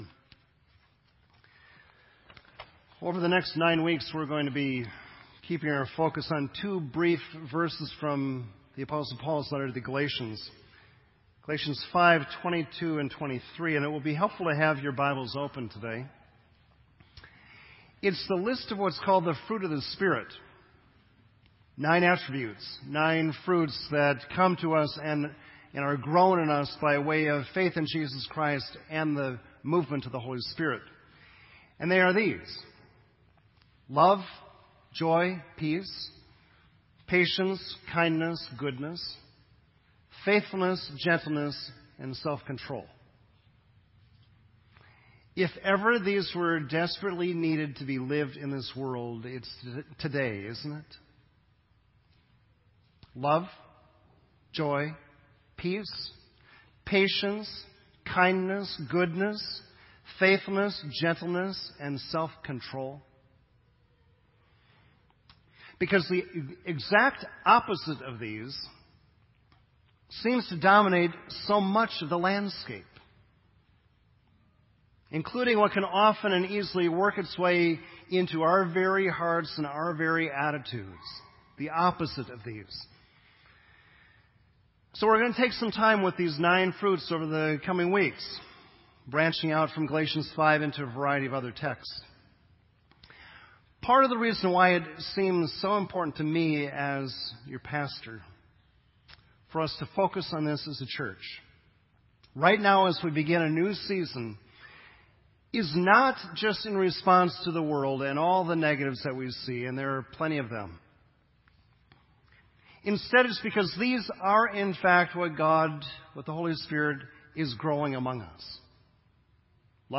This entry was posted in Sermon Audio on September 15